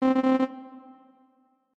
Error Sound
android application computer error game interface machine popup sound effect free sound royalty free Memes